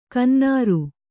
♪ kannāru